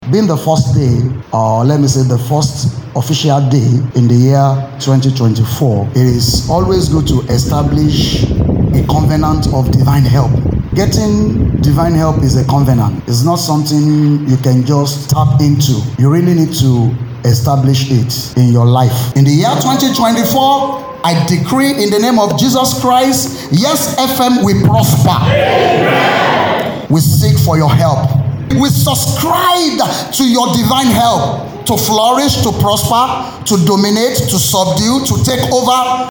As 2024 begins, 101.7 YesFM staff and management came together today for a Prayer Session, setting a positive tone for the year ahead.